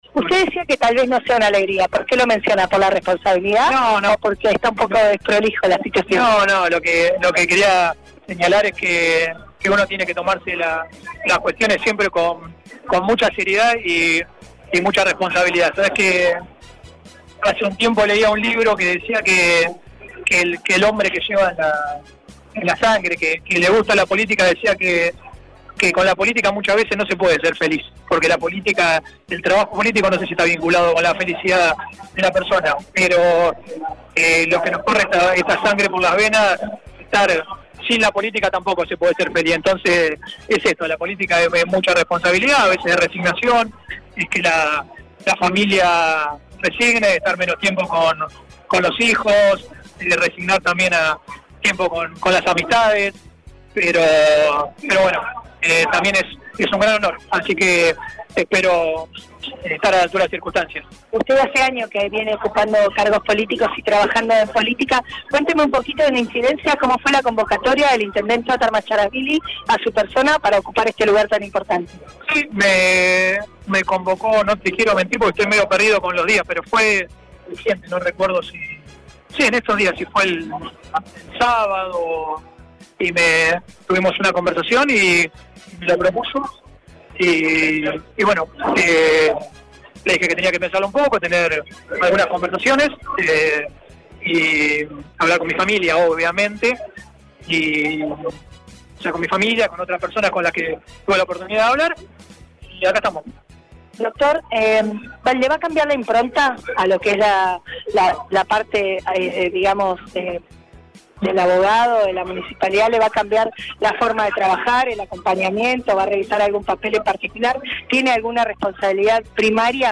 Luego de la conferencia de prensa, el flamante asesor legal dialogó con LA MAÑANA DE HOY: